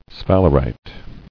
[sphal·er·ite]